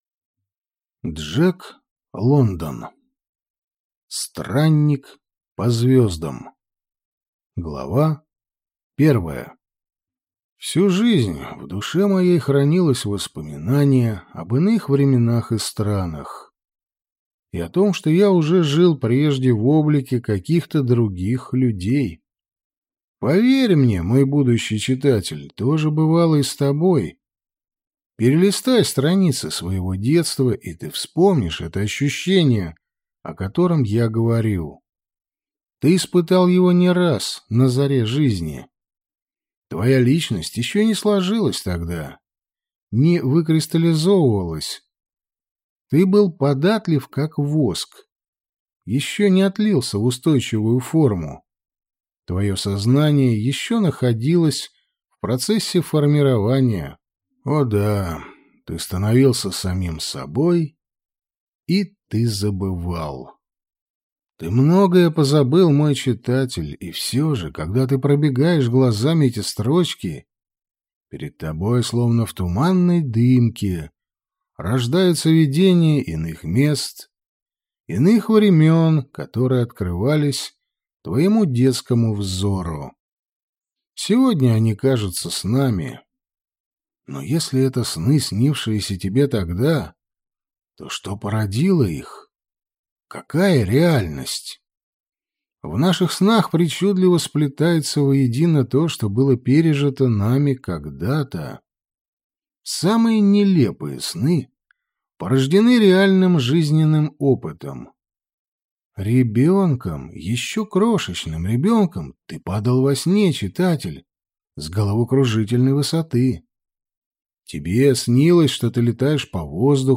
Аудиокнига Странник по звездам - купить, скачать и слушать онлайн | КнигоПоиск